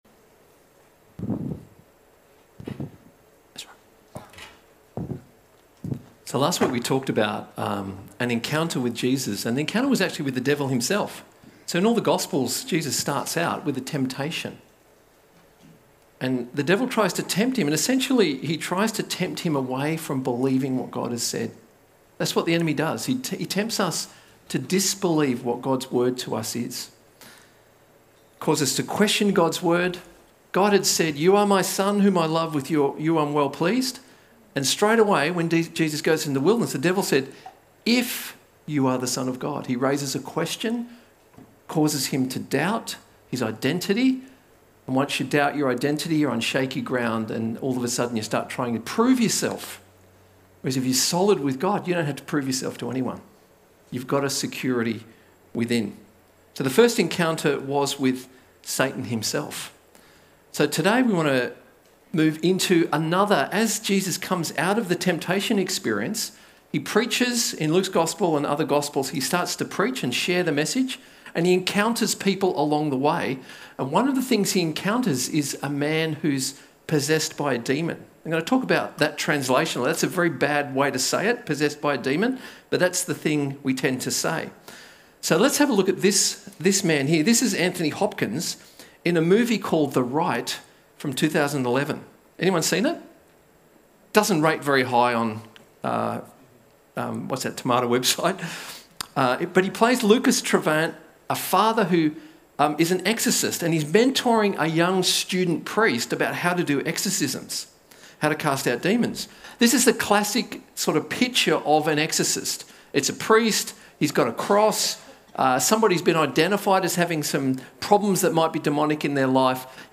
A message from the series "Encounters with Jesus."